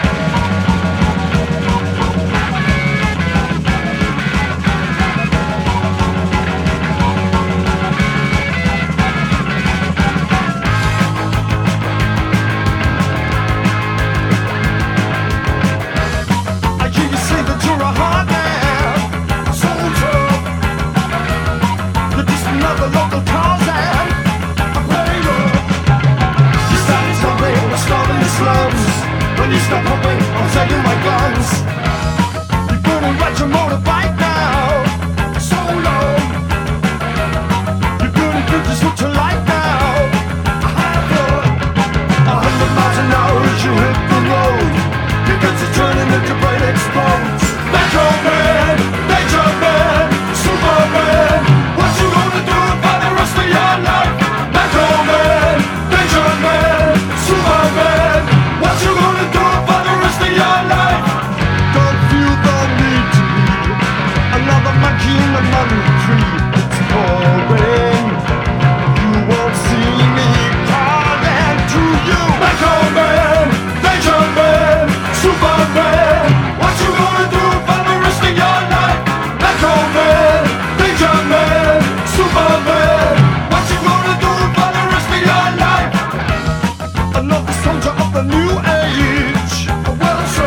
NEO MODS / MOD REVIVAL (UK)
ガレージーでかっこいい94年ネオモッド・コンピ！